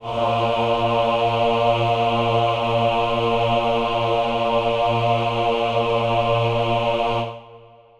Choir Piano (Wav)
A#2.wav